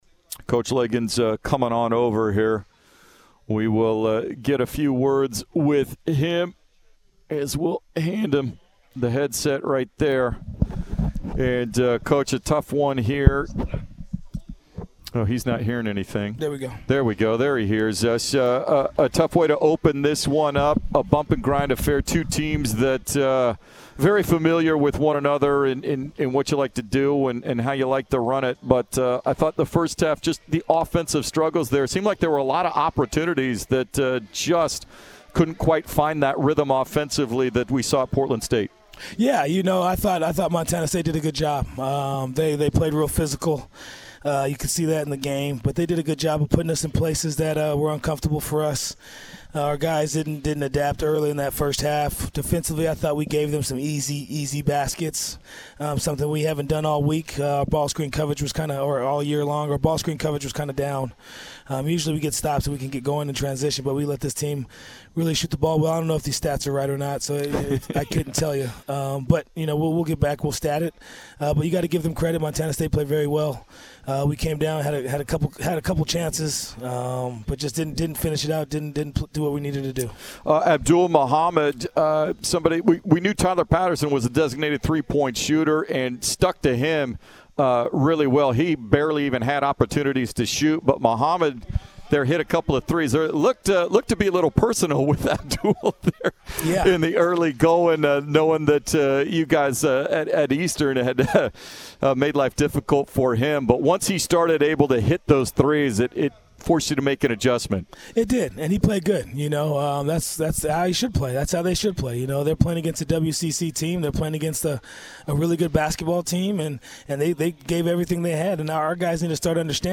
Men's Basketball Post-Game Interview